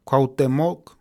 Cuauhtémoc (Nahuatl pronunciation: [kʷaːʍˈtemoːk]